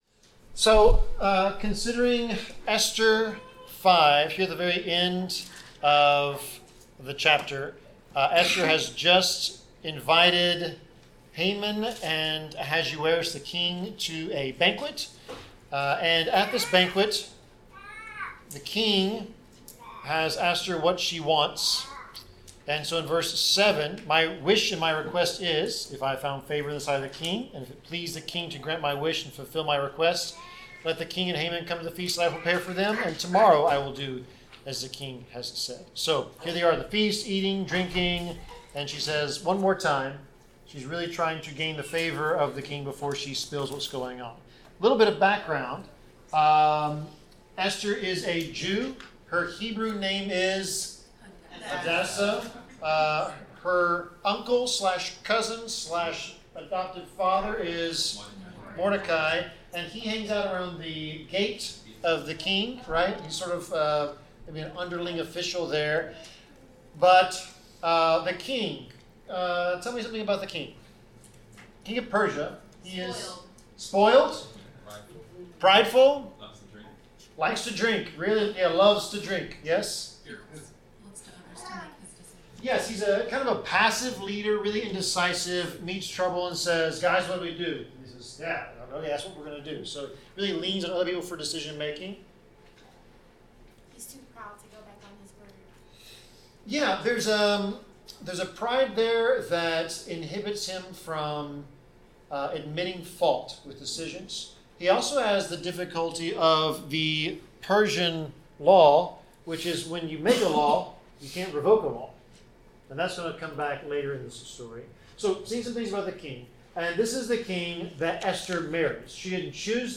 Bible class: Esther 5-8
Passage: Esther 5-8 Service Type: Bible Class